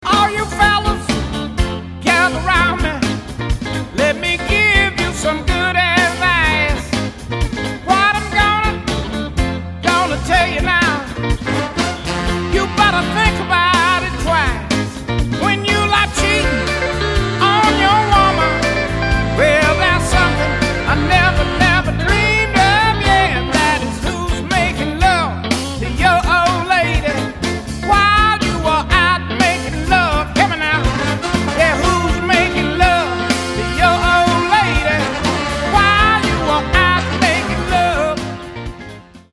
The Best in Soul, Rhythm & Blues, and Carolina Beach Music